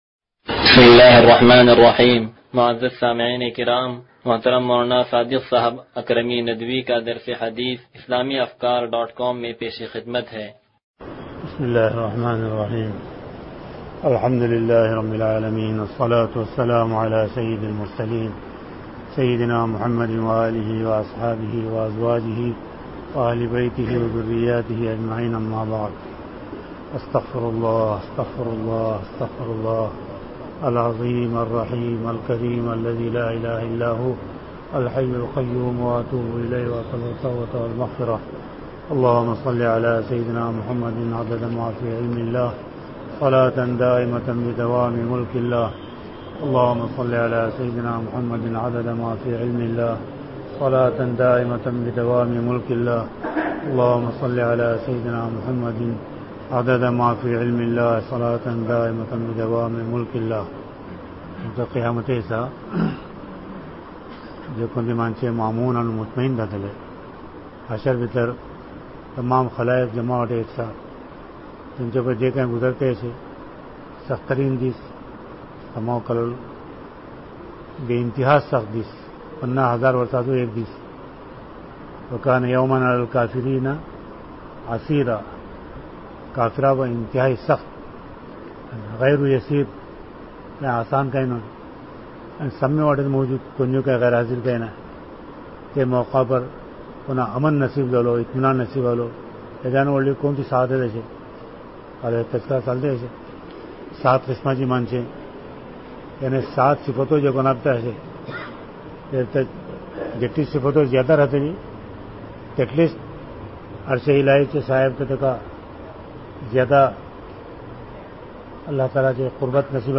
درس حدیث نمبر 0181